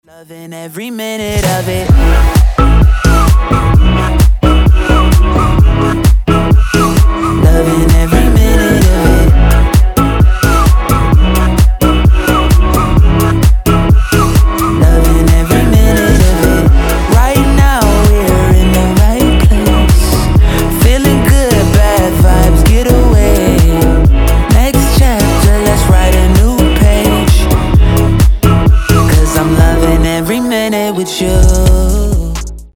• Качество: 320, Stereo
свист
красивый мужской голос
веселые
Танцевальный поп рингтон со свистом